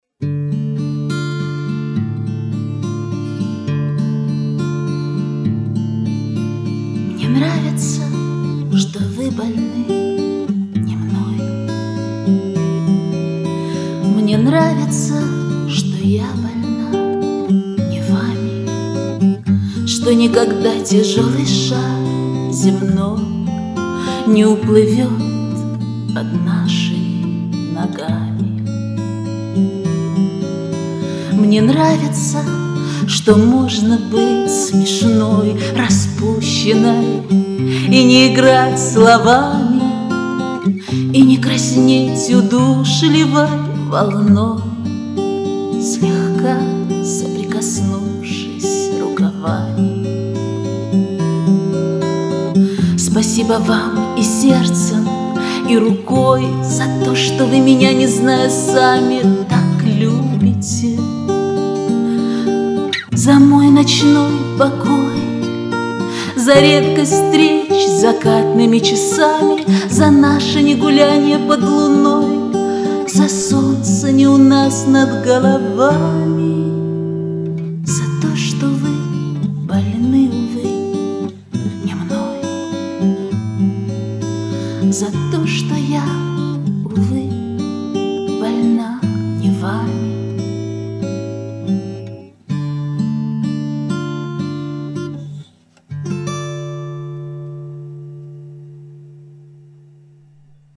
Комментарий инициатора: а как вы слышите этот романс?